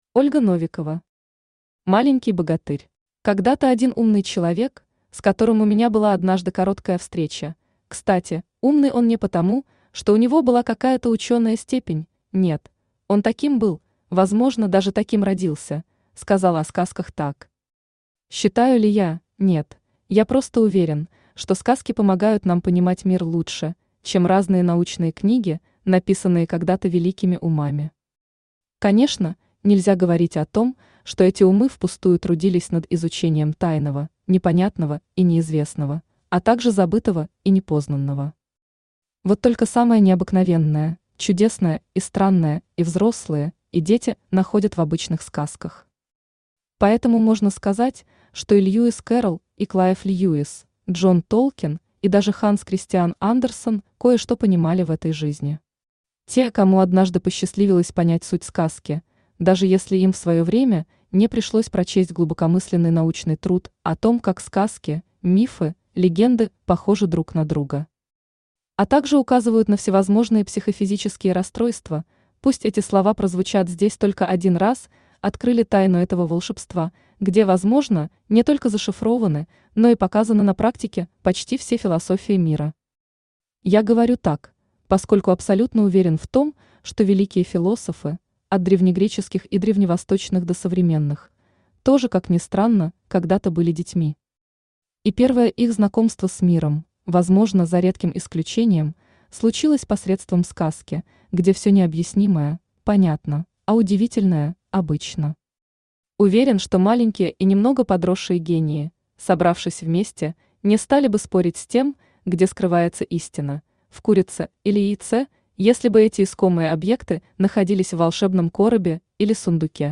Аудиокнига Маленький богатырь | Библиотека аудиокниг
Aудиокнига Маленький богатырь Автор Ольга Викторовна Новикова Читает аудиокнигу Авточтец ЛитРес.